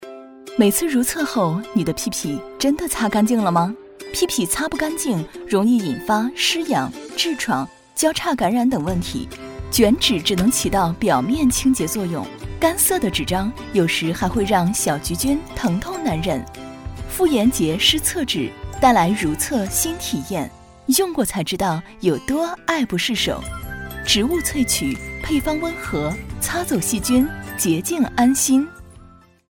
女267-广告-妇炎洁湿厕纸.mp3